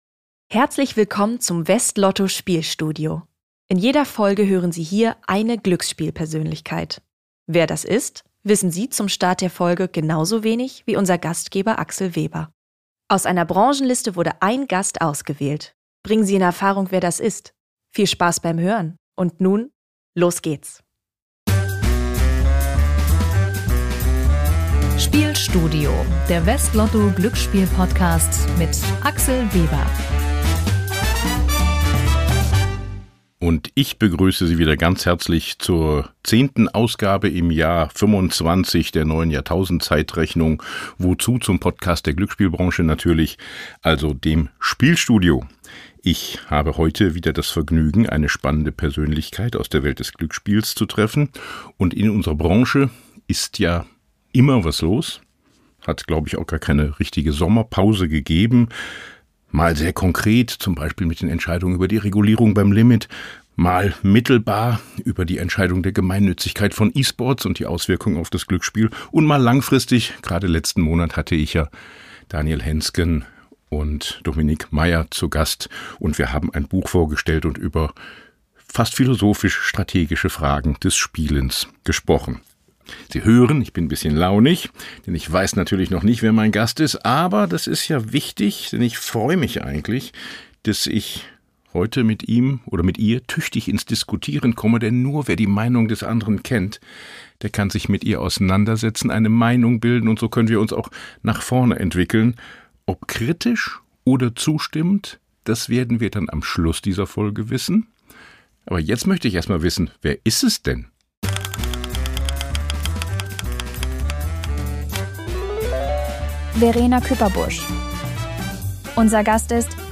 Hinweis: Ein Zuspieler in diesem Podcast wurden mit einer künstlichen Stimme erzeugt, die von einem KI-System erstellt wurde.